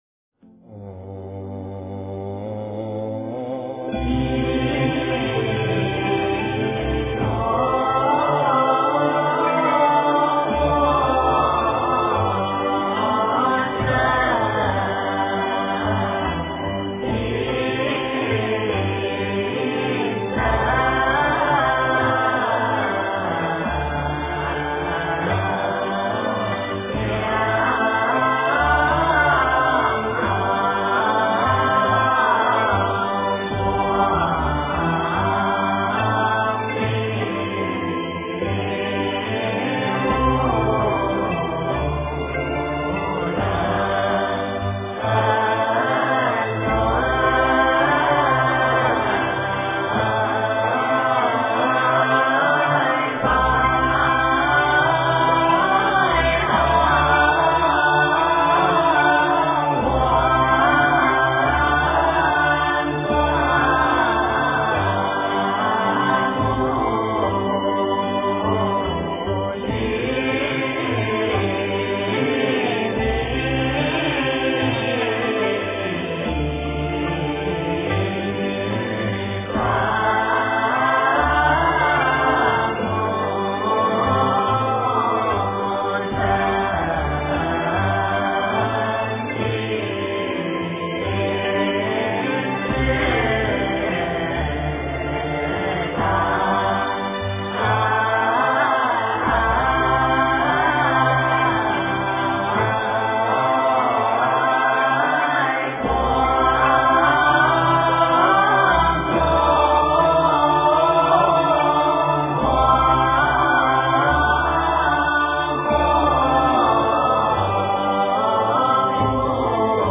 赞佛偈--佛光山中国佛教研究院 经忏 赞佛偈--佛光山中国佛教研究院 点我： 标签: 佛音 经忏 佛教音乐 返回列表 上一篇： 八十八佛大忏悔文-闽南语--圆光佛学院众法师 下一篇： 南无本师释迦牟尼佛--中国佛学院法师 相关文章 地藏菩萨圣号--妙明真觉 地藏菩萨圣号--妙明真觉...